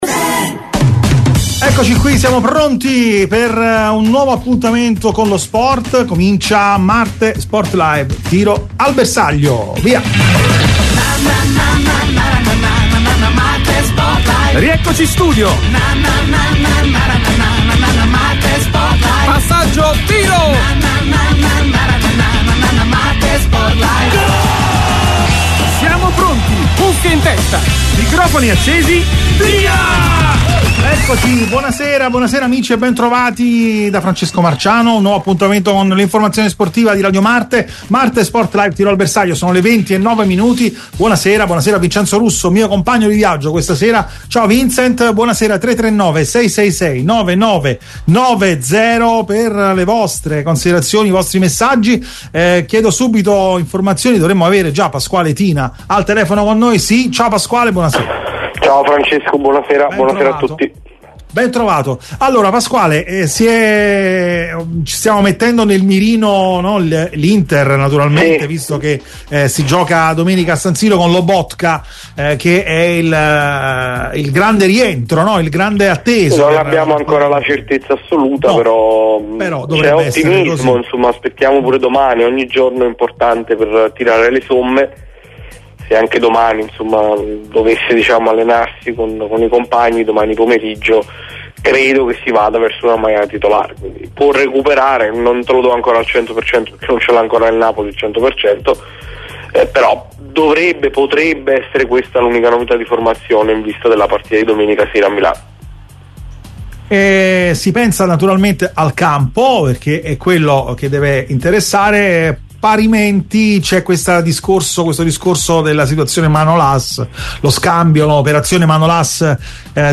MARTE SPORT LIVE è UNA TRASMISSIONE SPORTIVA, UN TALK CON OSPITI PRESTIGIOSI, OPINIONISTI COMPETENTI, EX TECNICI E GIOCATORI DI VALORE, GIORNALISTI IN CARRIERA E PROTAGONISTI DEL CALCIO ITALIANO E INTERNAZIONALE.